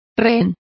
Complete with pronunciation of the translation of pawns.